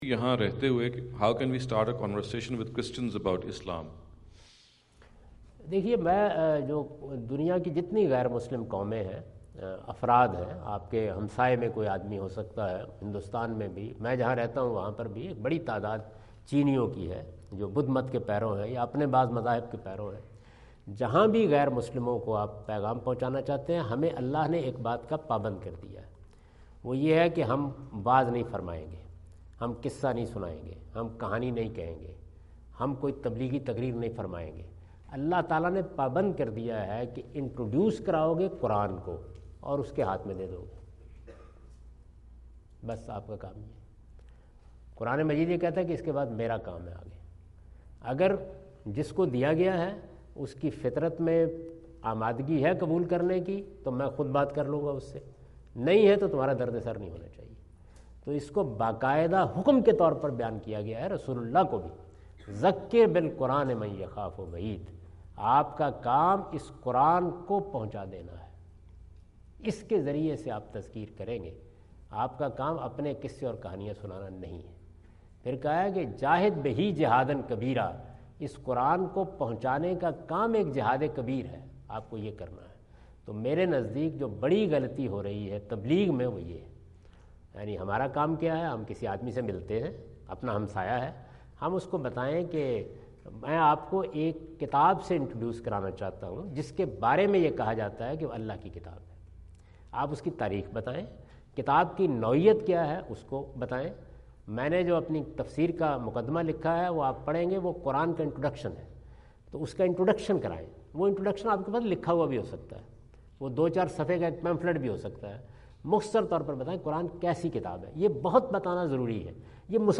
Category: English Subtitled / Questions_Answers /
Javed Ahmad Ghamidi answer the question about "How to Preach Islam in the West?" asked at Aapna Event Hall, Orlando, Florida on October 14, 2017.
جاوید احمد غامدی اپنے دورہ امریکہ 2017 کے دوران آرلینڈو (فلوریڈا) میں "مغرب میں اسلام کی تبلیغ کیسے کی جائے؟" سے متعلق ایک سوال کا جواب دے رہے ہیں۔